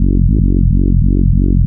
BL 144-BPM 1-F#.wav